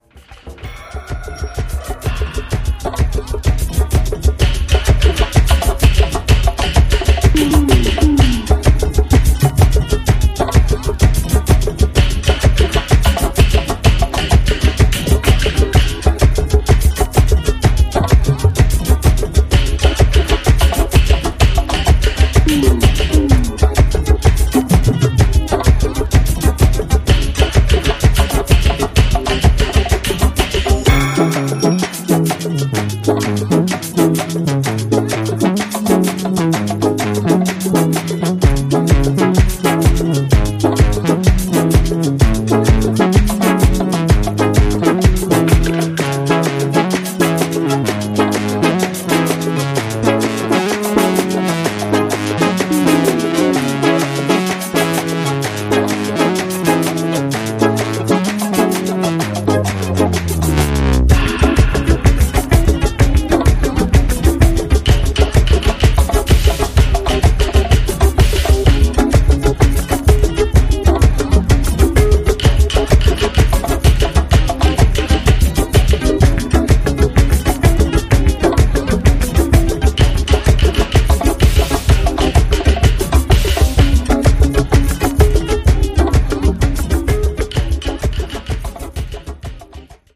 Disco / Balearic